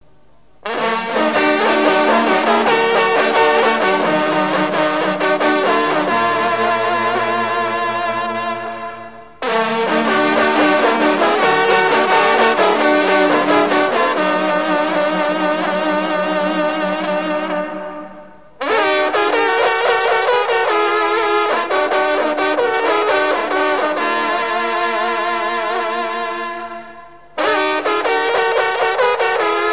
rencontre de sonneurs de trompe
Pièce musicale éditée